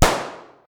pistol-shot.ogg